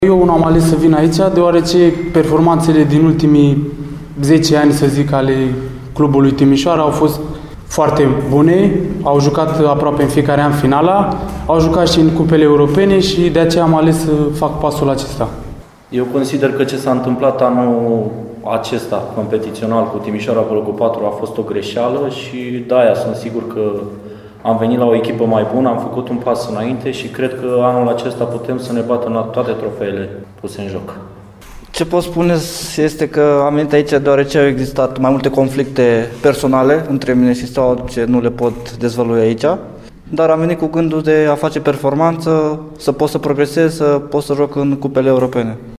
Îi ascultăm alături pe cei trei jucători veniți de la Steaua